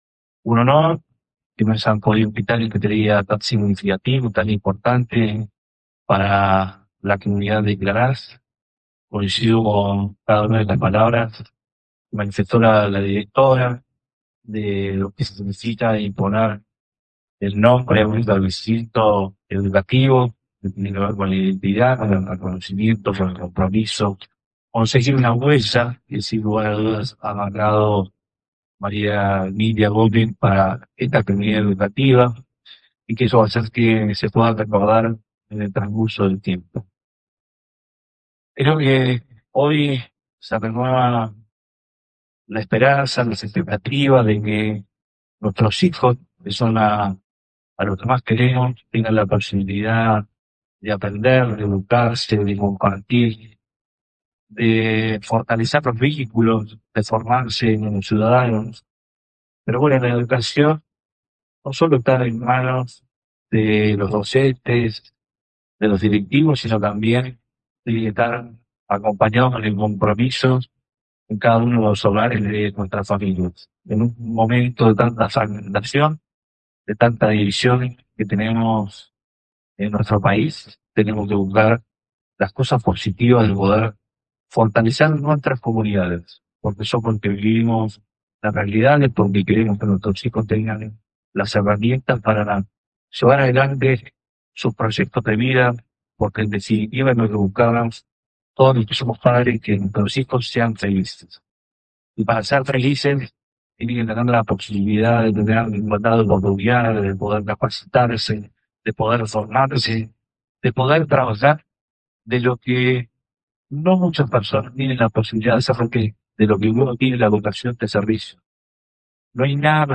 El intendente participó del inicio del ciclo lectivo en la Secundaria Nº 12 de Claraz
10-03-AUDIO-Arturo-Rojas.mp3